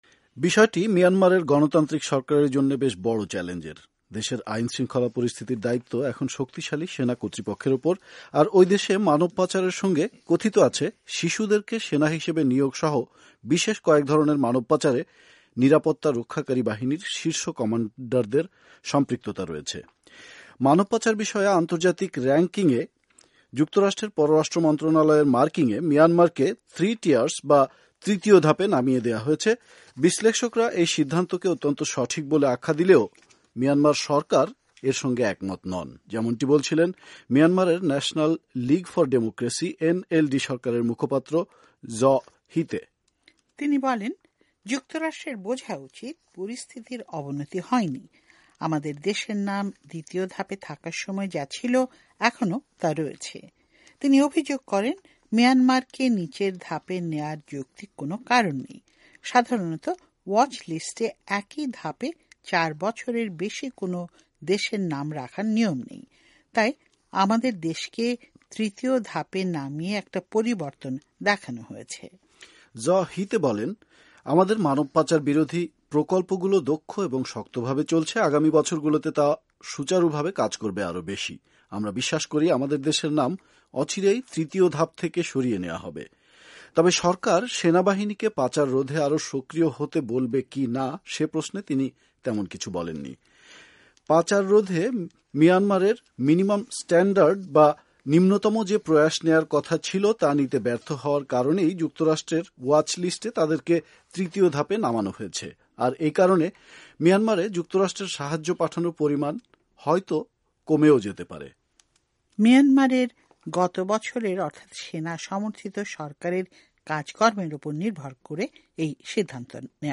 by ভয়েস অফ আমেরিকা